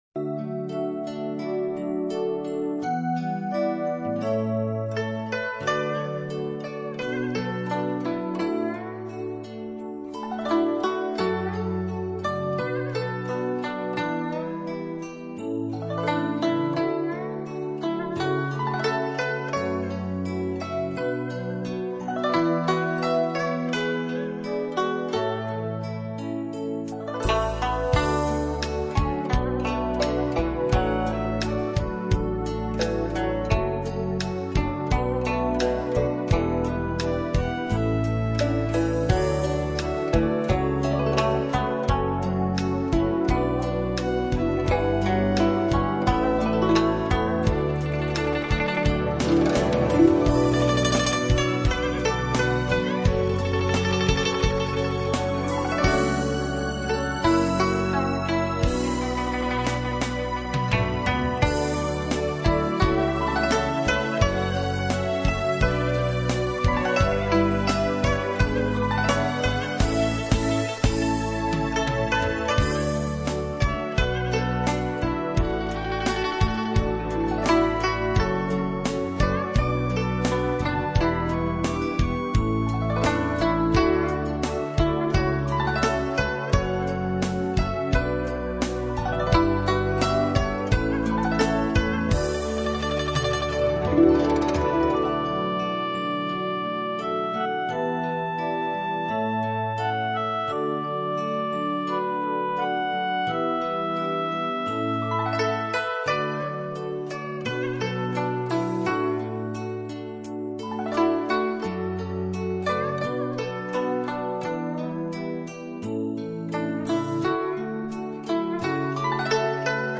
引用: 俺也来段古筝。。。。。。